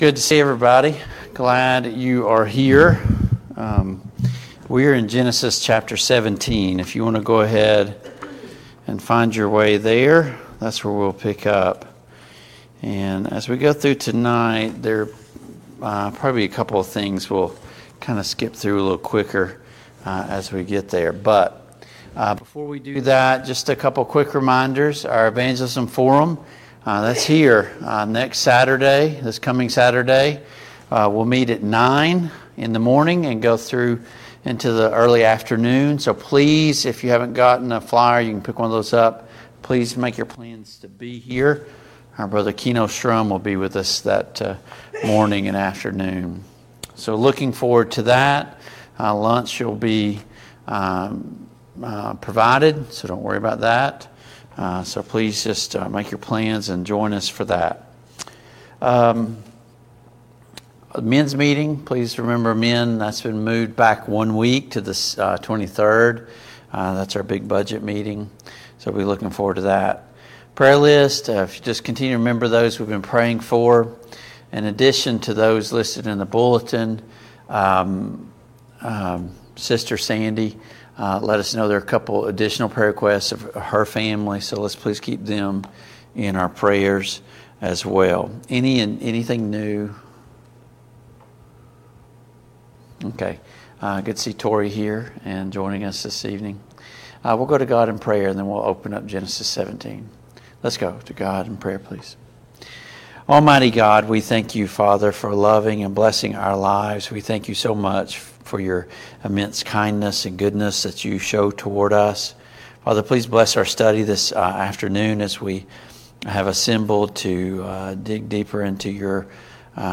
Service Type: Family Bible Hour Topics: Abraham and Sarah , God's covenant with Abraham